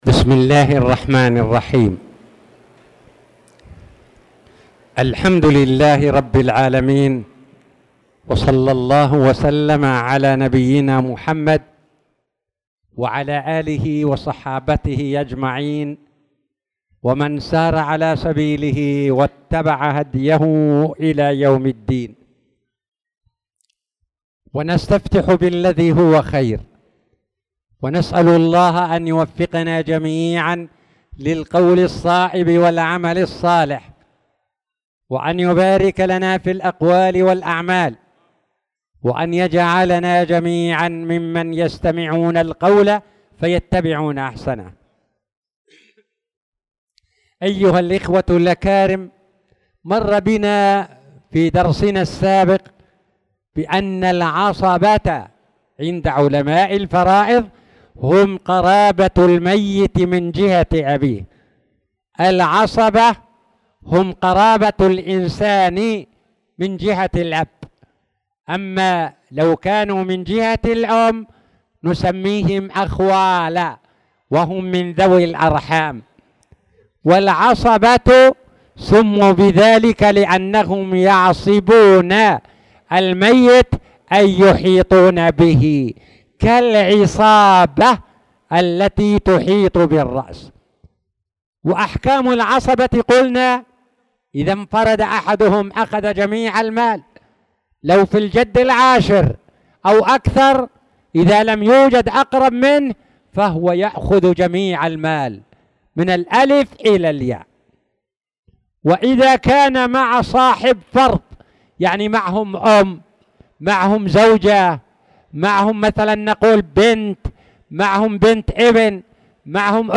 تاريخ النشر ٢٩ شوال ١٤٣٧ هـ المكان: المسجد الحرام الشيخ